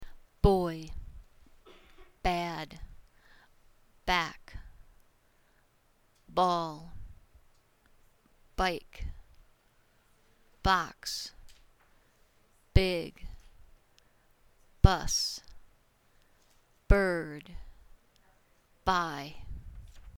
Pronunciation Practice
• You will hear a word then a pause.